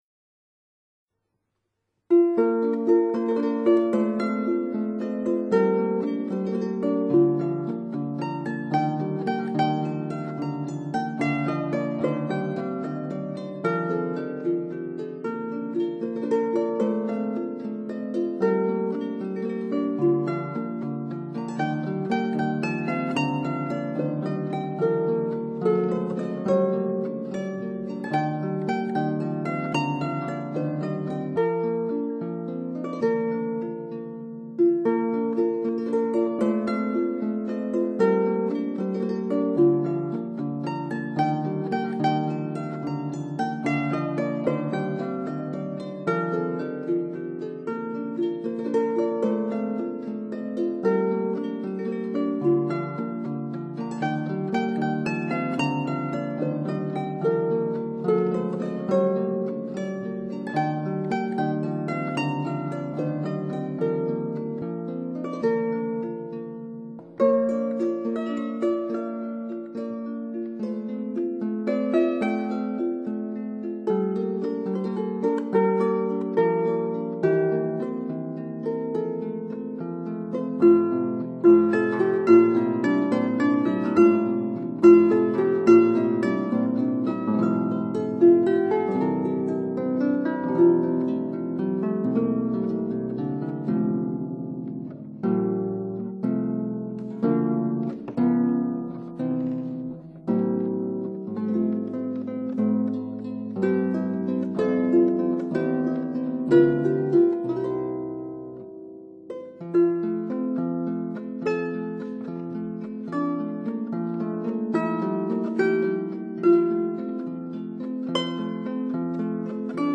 Single Action Harp